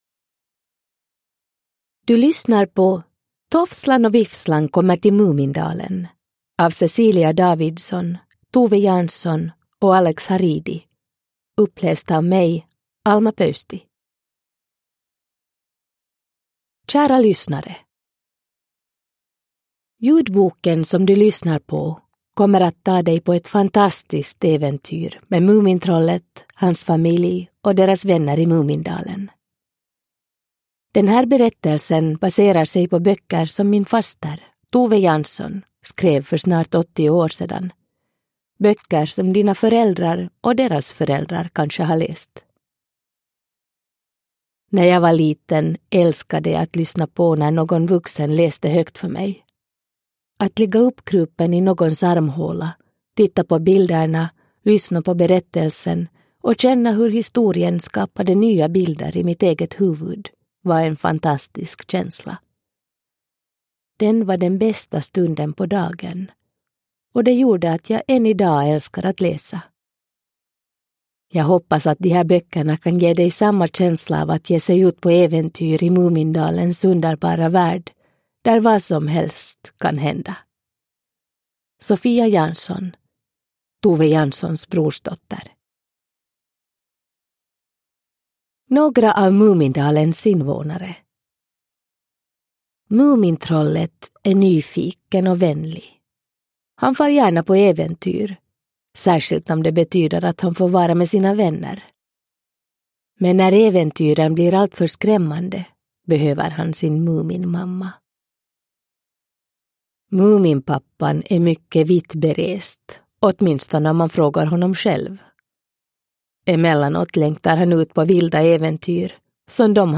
Tofslan och Vifslan kommer till Mumindalen – Ljudbok
Uppläsare: Alma Pöysti